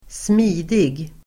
Uttal: [²sm'i:dig]